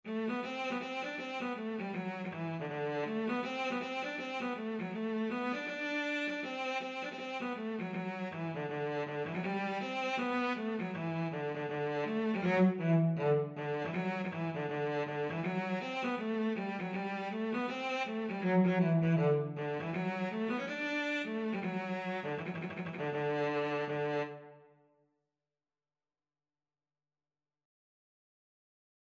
Traditional Music of unknown author.
4/4 (View more 4/4 Music)
D major (Sounding Pitch) (View more D major Music for Cello )
Cello  (View more Easy Cello Music)
Traditional (View more Traditional Cello Music)